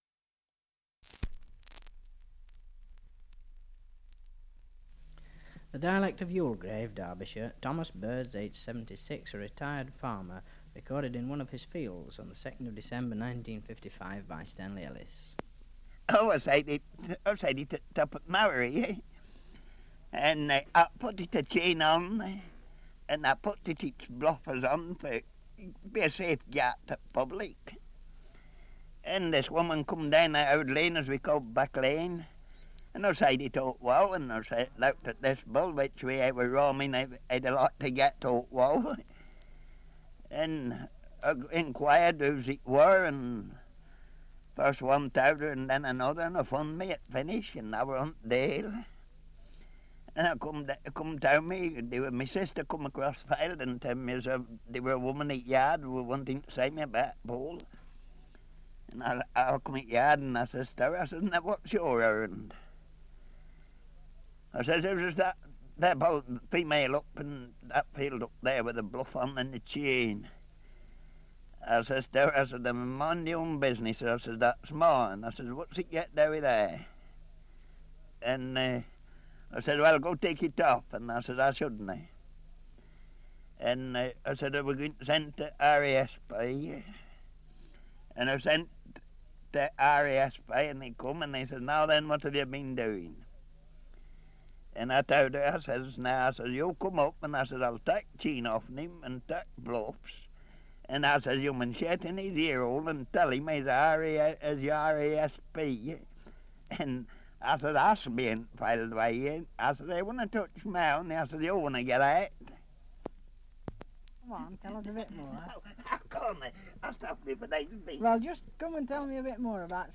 Survey of English Dialects recording in Youlgreave, Derbyshire
78 r.p.m., cellulose nitrate on aluminium